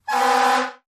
fo_fireengine_horn_01_hpx
Fire engines sounds horn. Siren, Fire Engine Horn Horn, Fire Engine